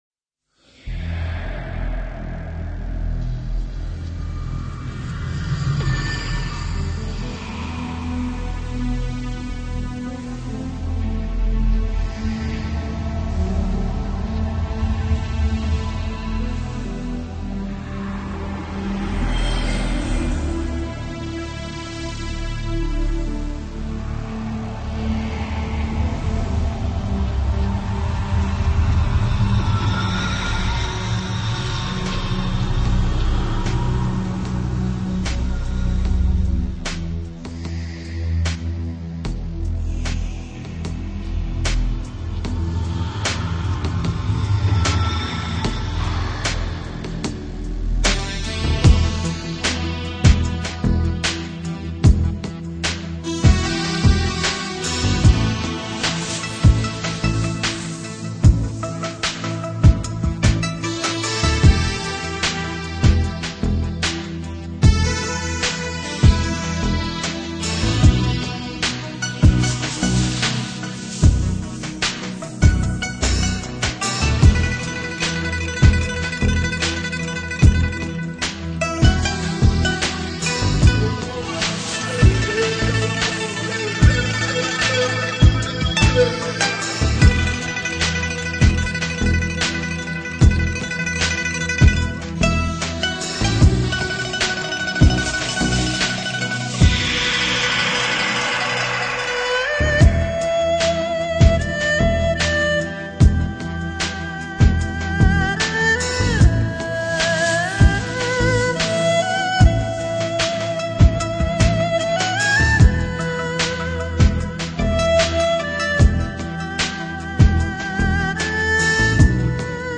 音乐类型：World music/New Age/Enigmatic